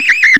Le fonctionnement est bizarre, car les sons qui sortent sont différents après chaque utilisation - mais pas tout le temps.
Vous aussi vous voulez utiliser le Synthétiseur Aléatoire du Microcosme ?